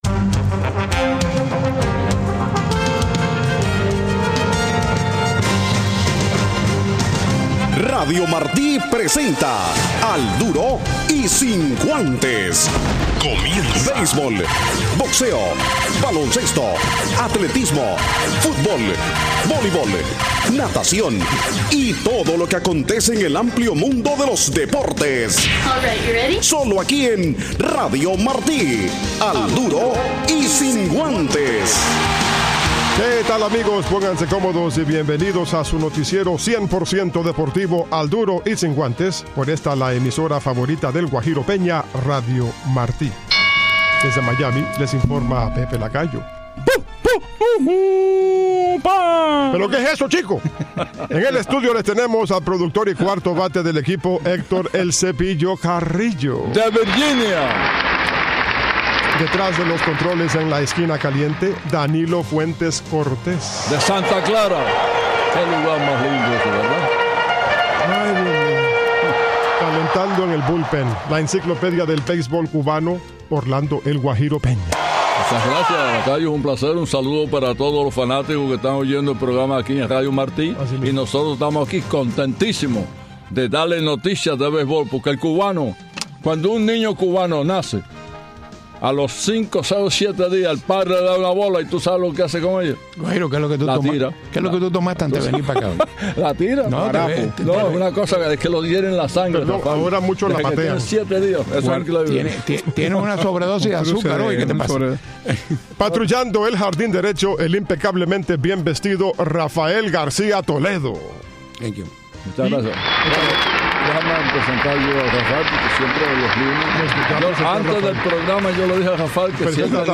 Al Duro del lunes y unas gratas entrevistas con Adeiny Hechavarria y Gio Gonzalez allá en el parque de los Marlins.